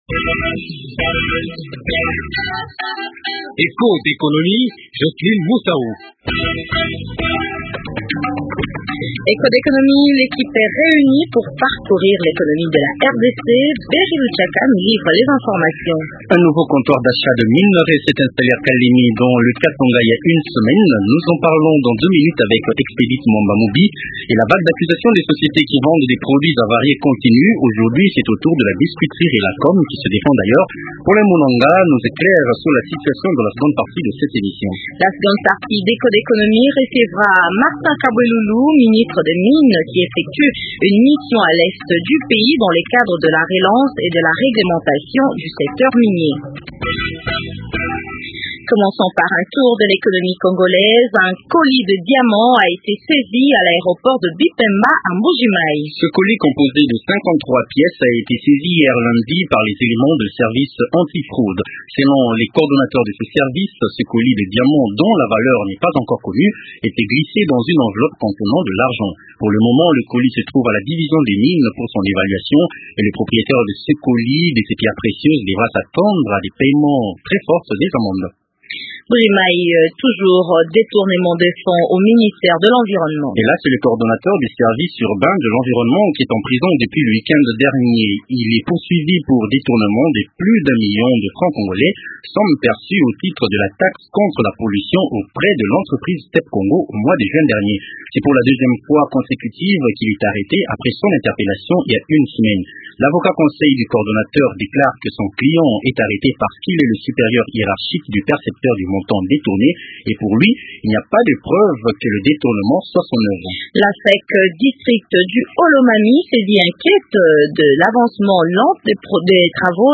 Il est l’Eco Invité.
Et au téléphone, c’est un nouveau comptoir d’achat des minerais à s’est installé Kalemie dans le Katanga.